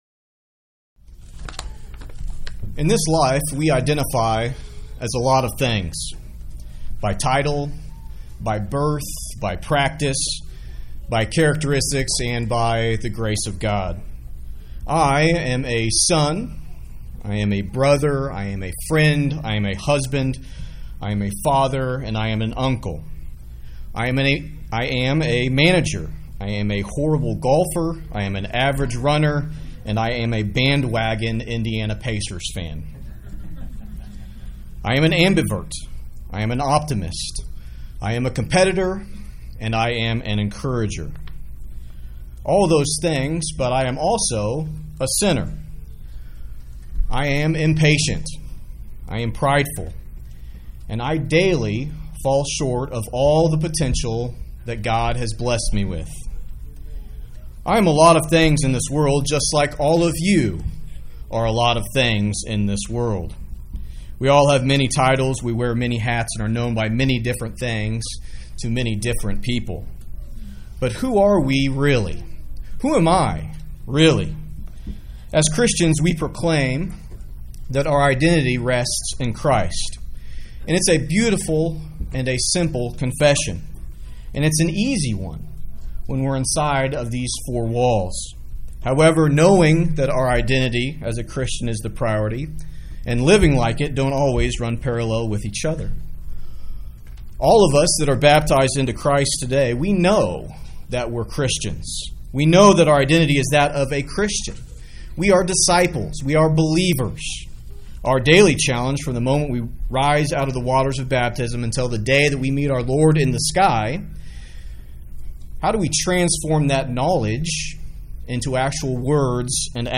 To play recording left-click on sermon title.